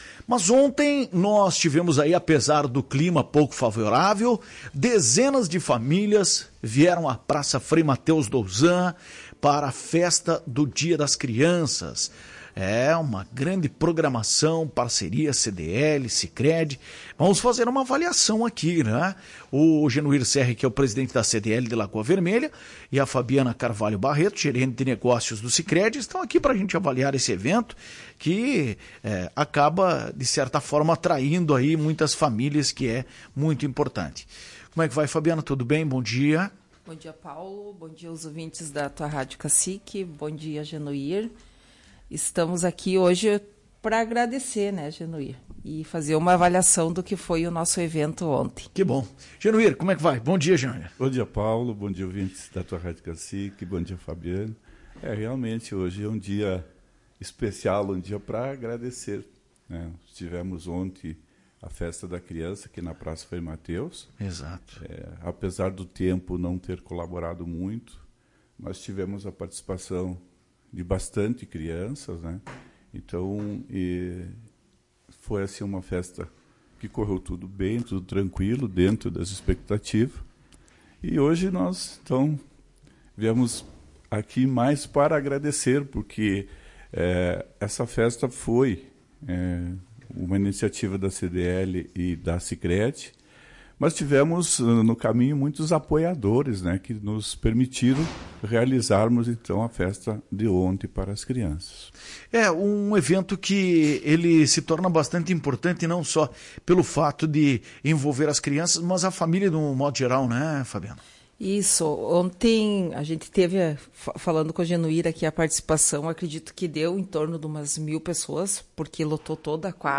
concederam entrevista à Tua Rádio Cacique e avaliaram o evento.